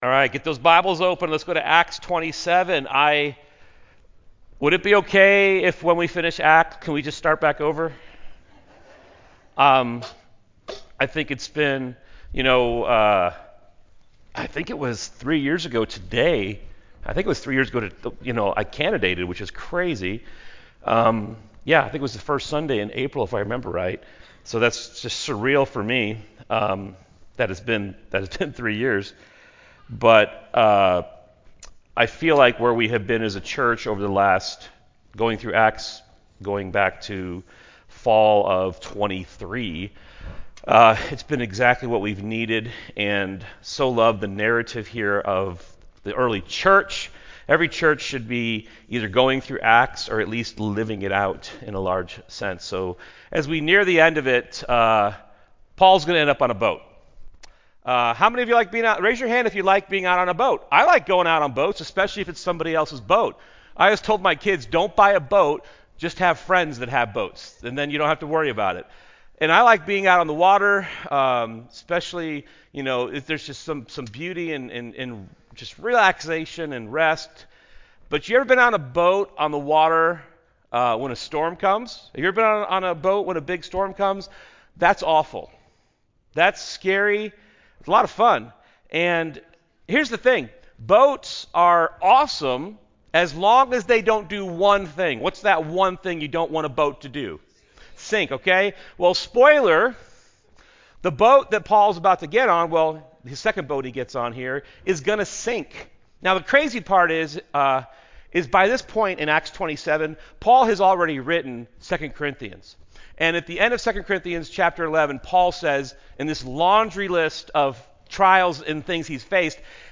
A message from the series "ACTS."
sermon